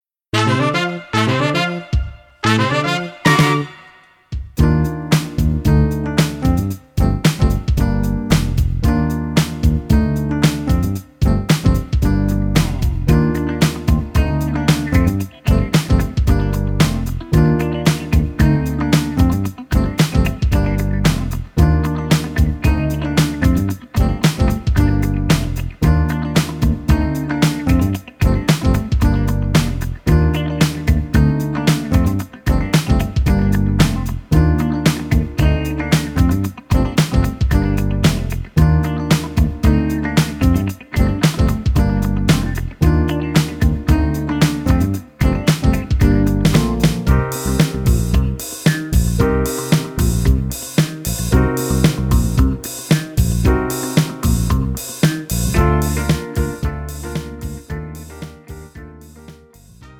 장르 pop 구분 Pro MR